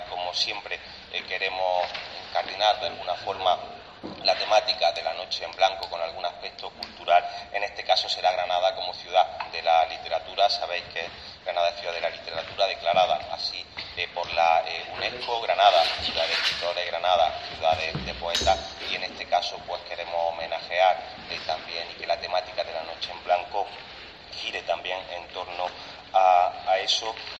Eduardo Castillo, concejal de comercio y turismo